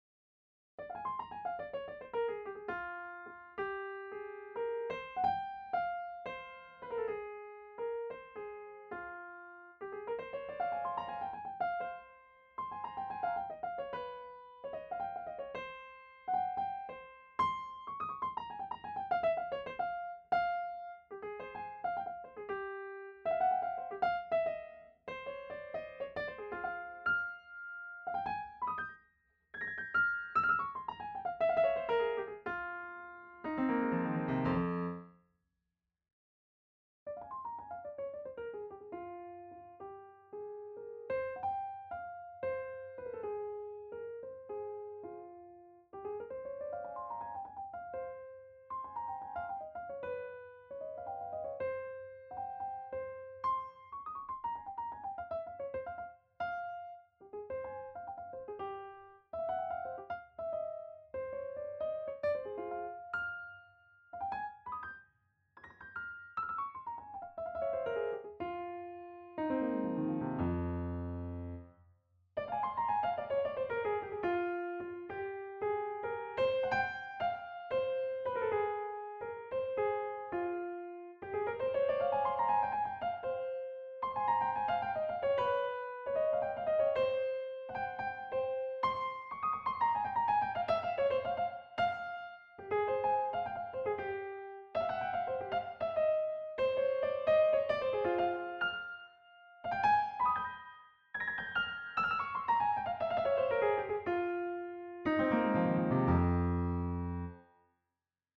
the room was hard walled and rectangular and it didn't sound quite right
I've cut out the solo, and taken the MIDI performance and stuck it on my current favourite sample the NI Noire, and also used the same track on Pianoteq 9 which is a synthesised piano VSTi - the track is mics (2 x AKG C451 probably, but could have been 414s - can't remember) followed by sample, then the synthesised. Attachments real-sample-synth.mp3 real-sample-synth.mp3 4.1 MB